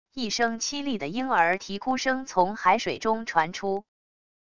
一声凄厉的婴儿啼哭声从海水中传出wav音频